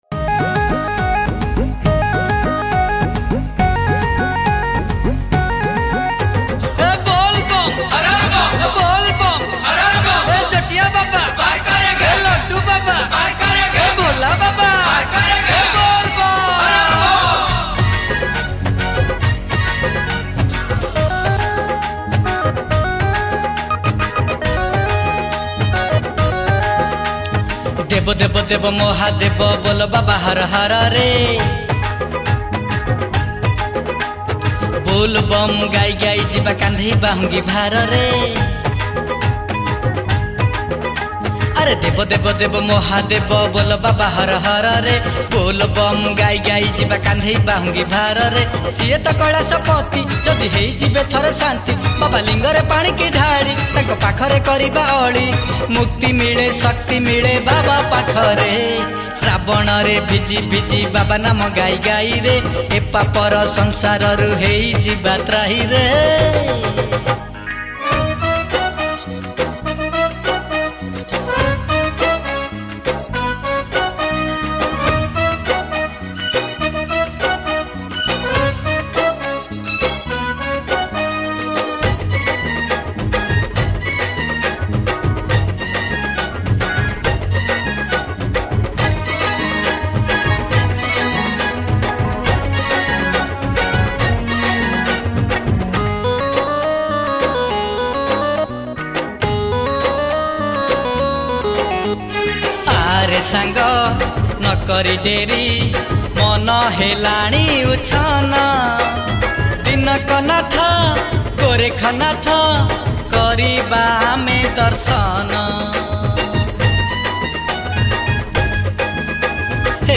Category : Bolbum Special Song